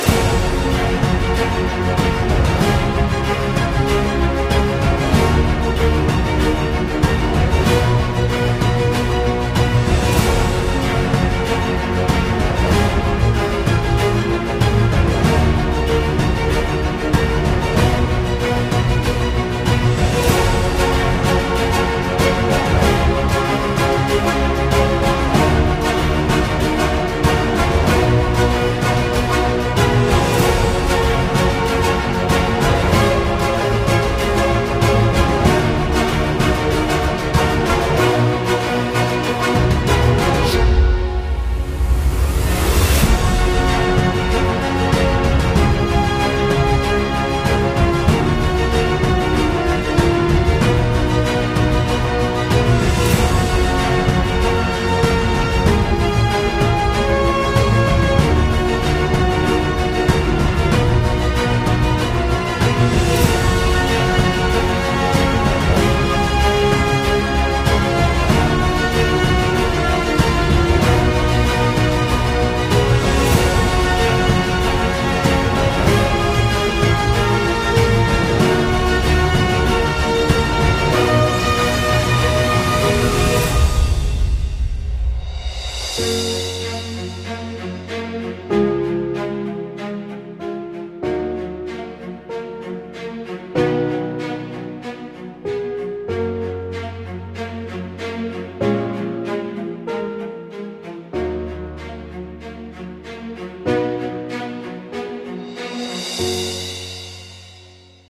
震撼大气壮观大场面激励人心